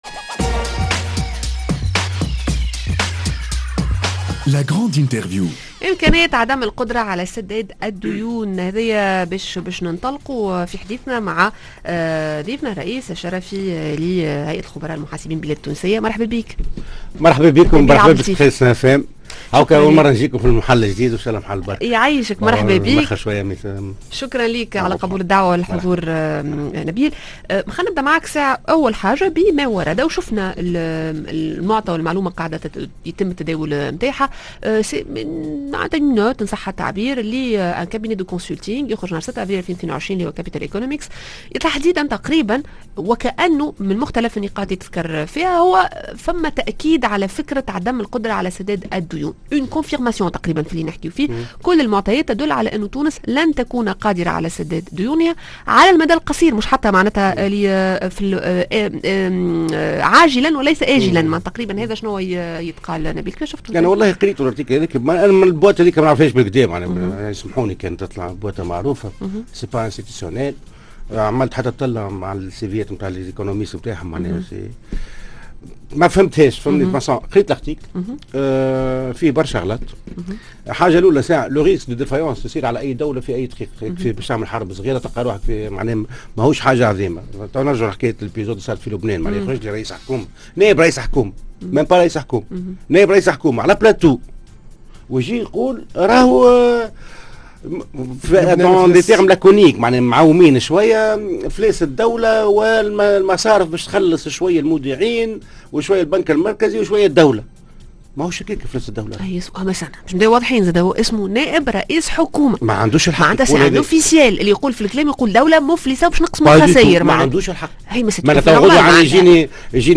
la grande interview